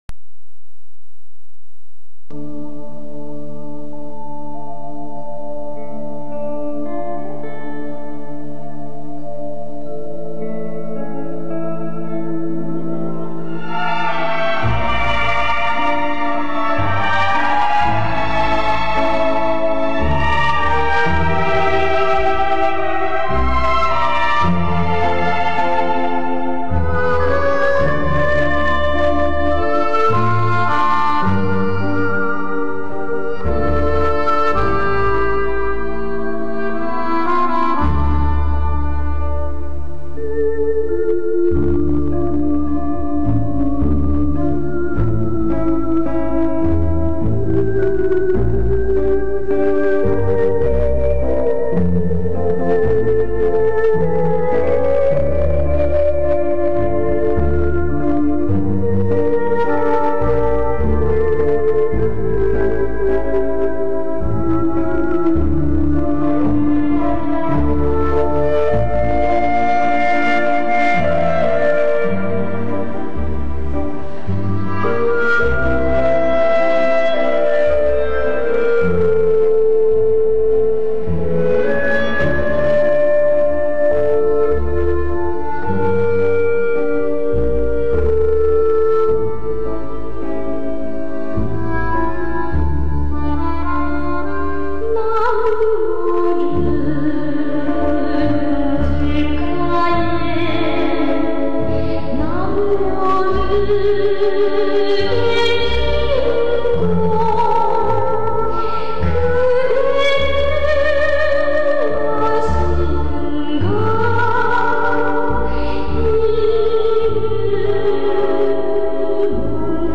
值得一提的是，该片的主题音乐具有朝鲜音乐浓郁的民族风情，在优美感伤的旋律之中蕴含着一种激昂的力量。
主题曲